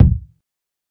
KICK_U_IN_TROUBLE_2.wav